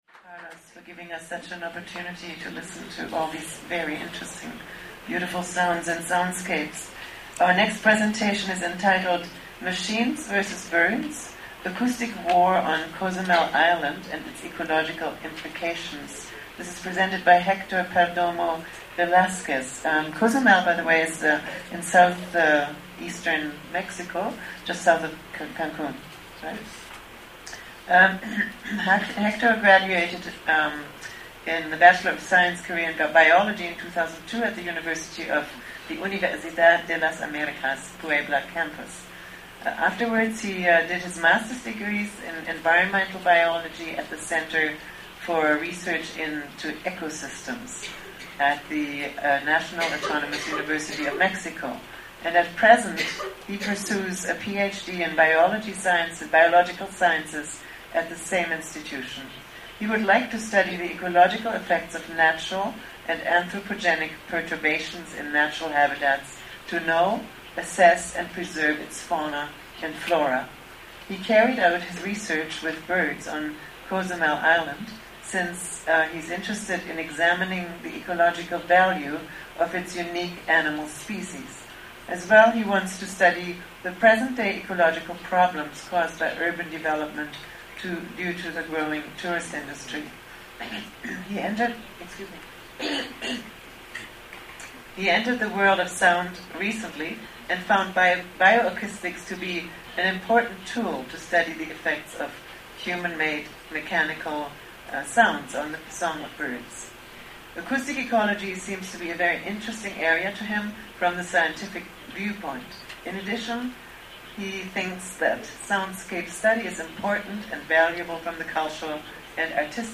En el marco del Foro Internacional de Ecología Acústica se presentó la ponencia: Máquinas Vs. Pájaros: guerra acústica en la isla de Cozumel y sus implicaciones ecológicas.